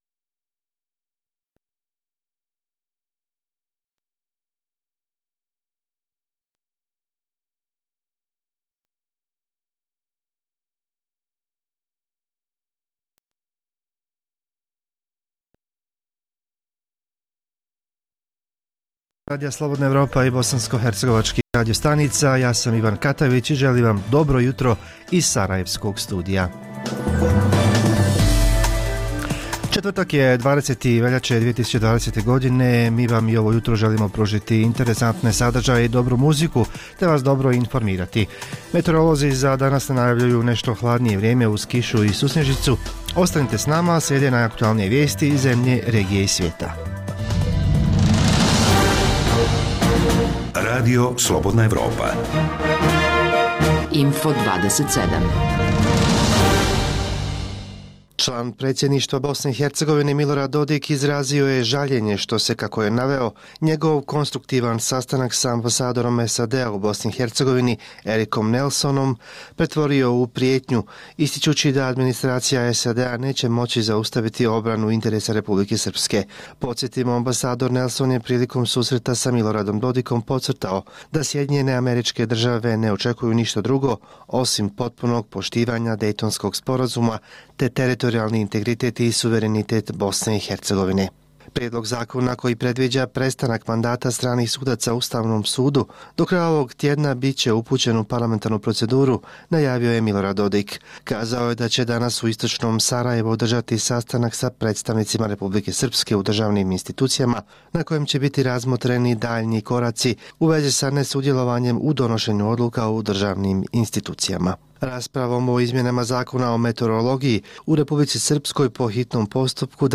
Jutarnji program za BiH koji se emituje uživo. Uz dobru muziku, poslušajte zanimljivosti iz Tuzle, Kreševa, Bihaća, Banjaluke, Mostara, Travnika.
Redovni sadržaji jutarnjeg programa za BiH su i vijesti i muzika.